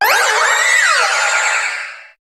Cri de Méga-Diancie dans Pokémon HOME.
Cri_0719_Méga_HOME.ogg